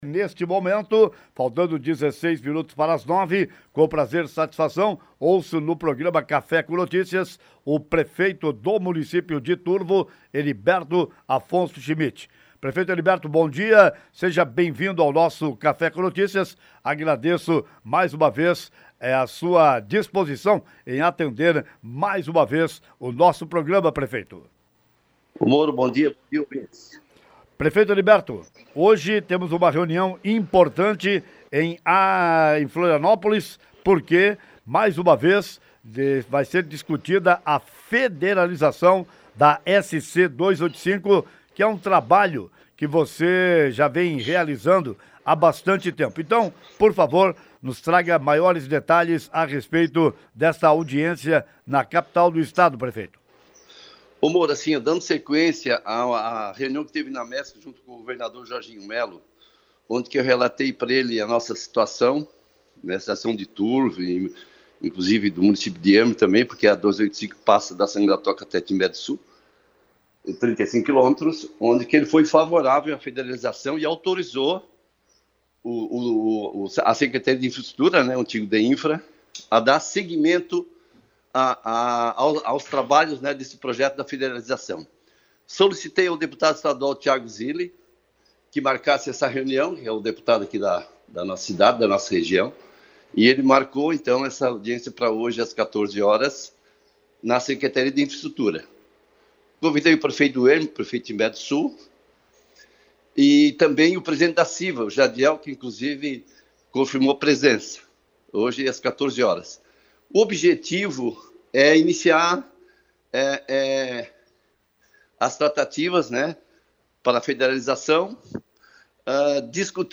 O prefeito Heriberto falou sobre o objetivo da reunião nesta manhã no programa Café com Notícias.
Entrevista-Com-Heriberto.mp3